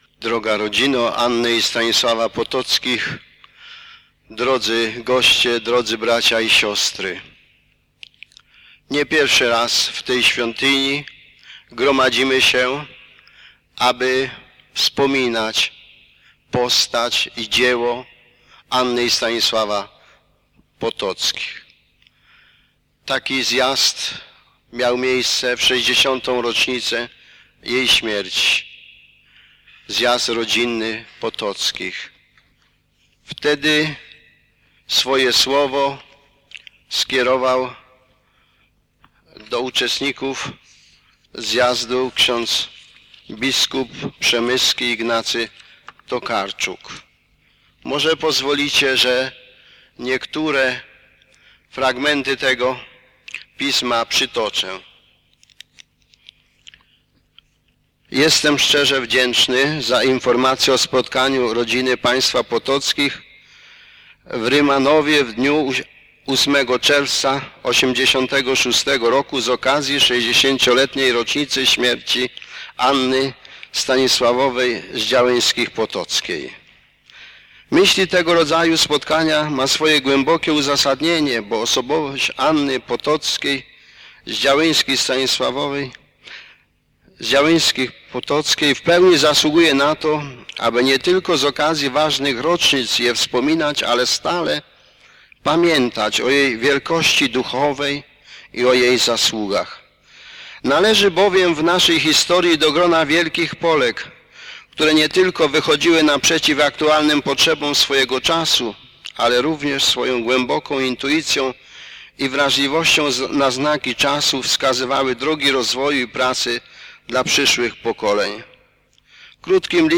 Ostatniego dnia maja, w święto Nawiedzenia NMP, odbyły się w Rymanowie uroczystości upamiętniające hrabinę Annę z Działyńskich Potocką, zasłużoną mieszkankę naszego miasta.
Homilia